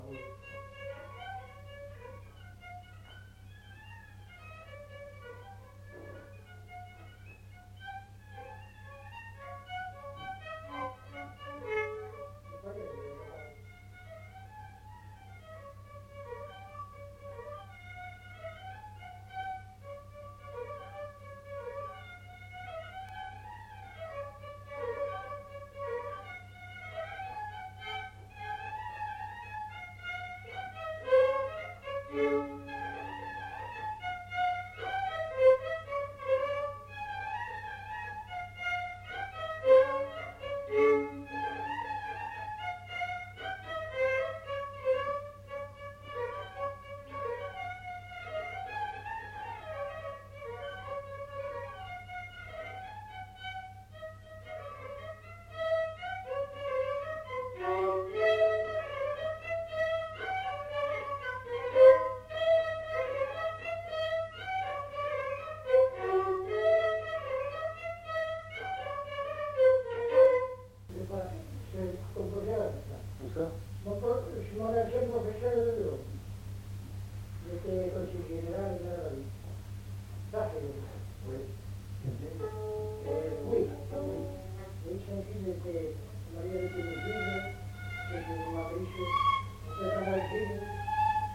Rondeau
Aire culturelle : Lomagne
Département : Gers
Genre : morceau instrumental
Instrument de musique : violon
Danse : rondeau
Deux violons. Deux rondeaux enchaînés.